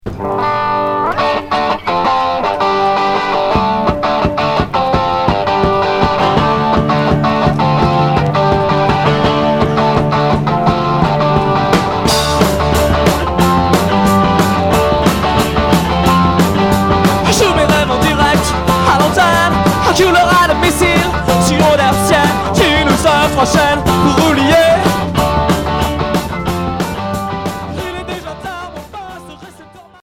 (live)
Punk rock